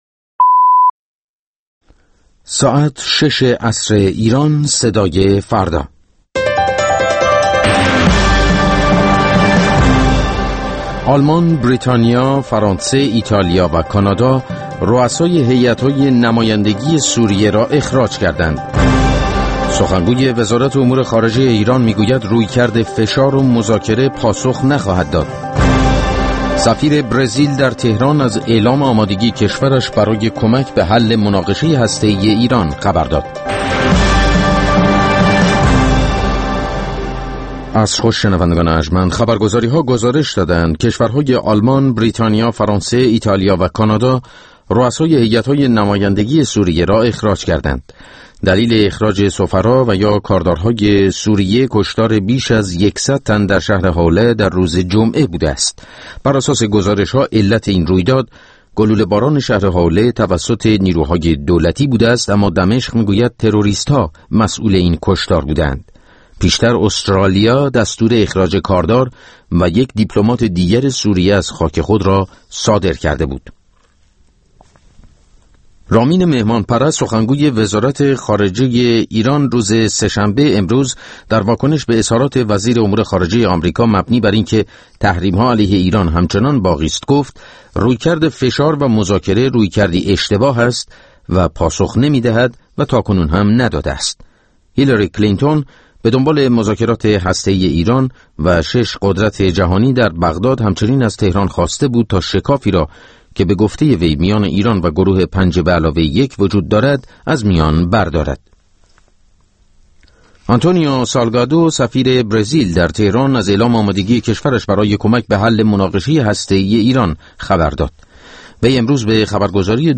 مستند رادیویی «انفرادی» هر هفته سه‌شنبه‌ها در ساعت ۱۸ به وقت ایران پخش می‌شود و روزهای پنجپنج‌شنبه ساعت ۱۴، روزهای جمعه ساعت ۹ صبح و یکشنبه‌ها ساعت ۲۳ به وقت ایران، تکرار می‌شود.